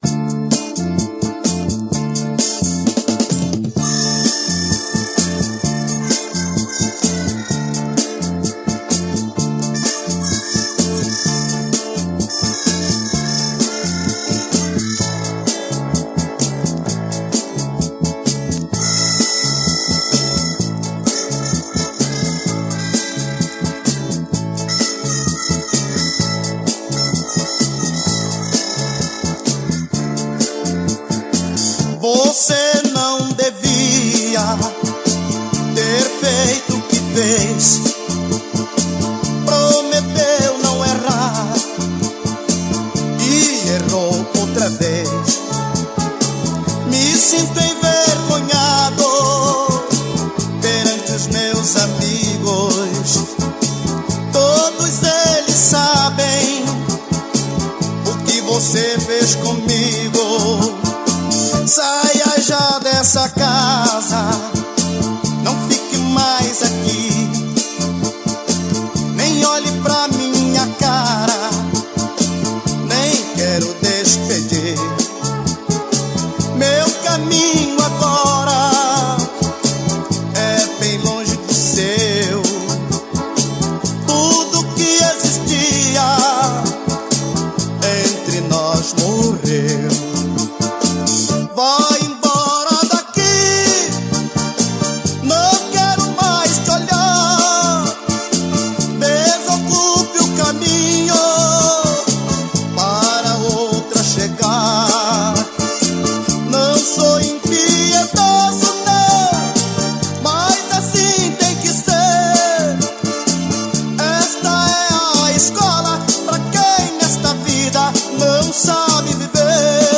Brega